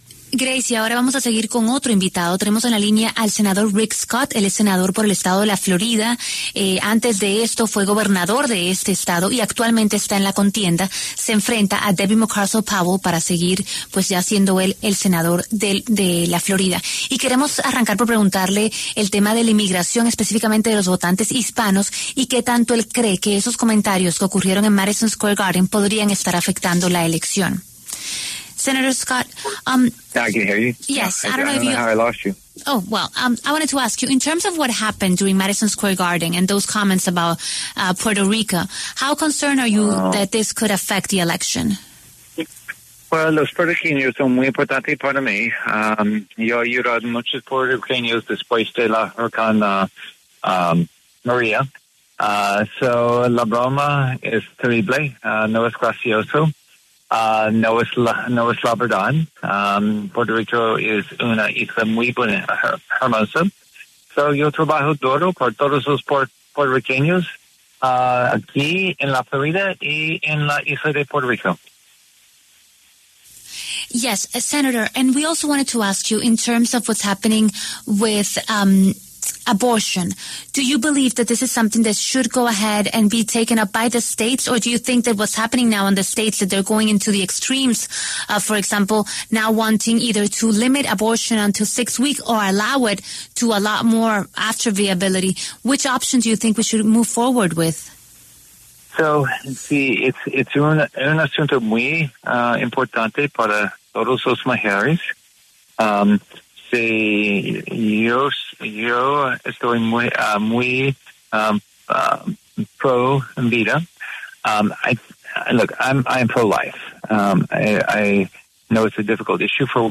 El senador Rick Scott, quien es miembro del Partido Republicano y fue gobernador de Florida entre 2011 y 2019, habló en La W sobre las elecciones que se llevan a cabo en Estados Unidos.